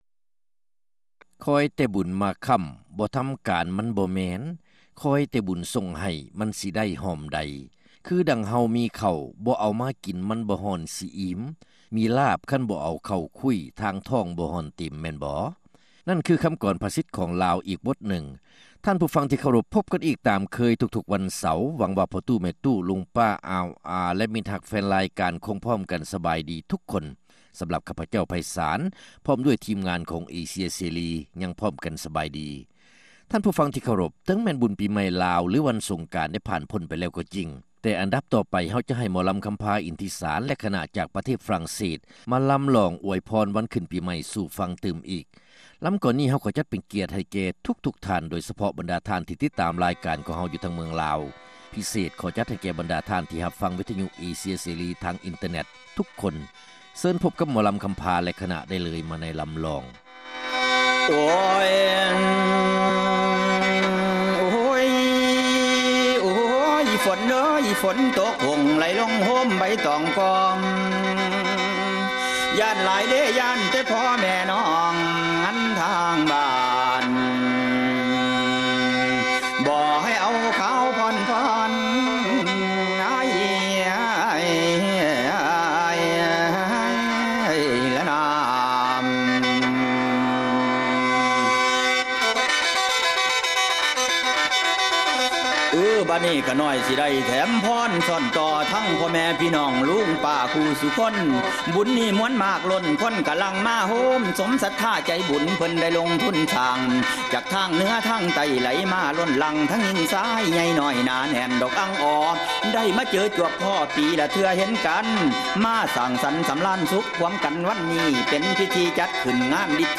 ຣາຍການໜໍລຳ ປະຈຳສັປະດາ ວັນທີ 21 ເດືອນ ເມສາ ປີ 2006